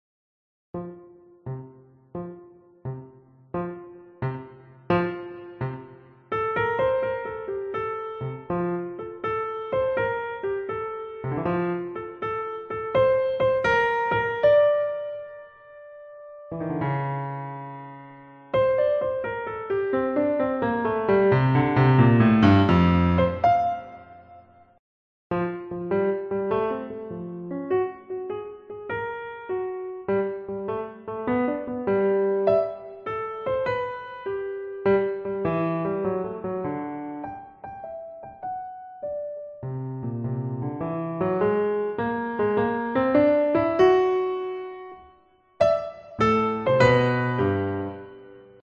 Level 1-Easy Piano Solos